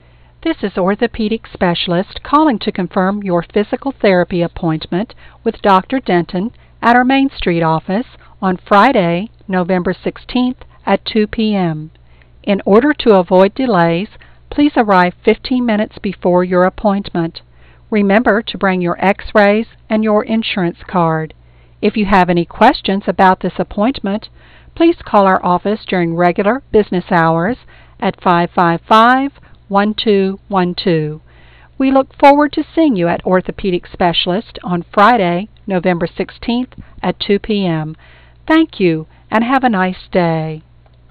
And you'll deliver your messages with professionally recorded, crystal-clear 16 bit sound.